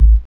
14SYN.BASS.wav